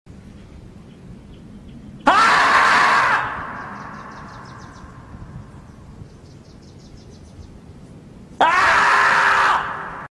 Catégorie Bruitages